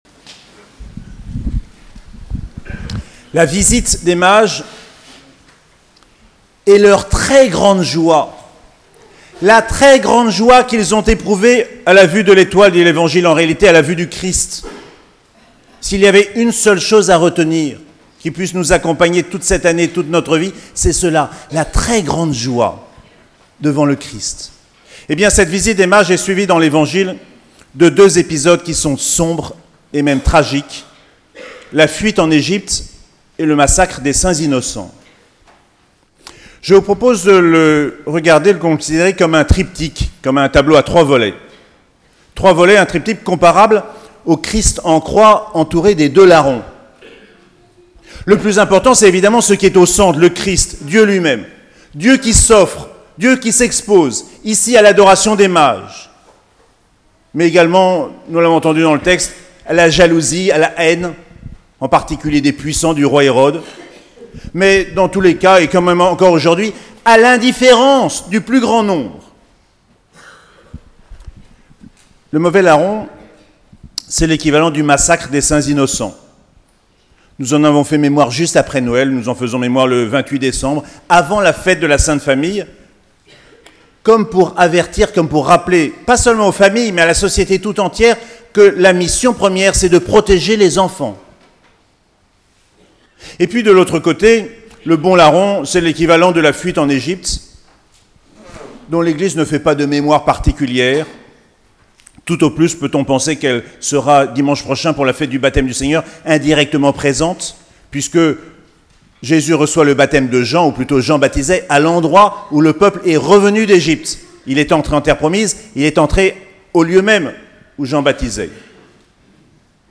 Solennité de l'Epiphanie - 6 janvier 2019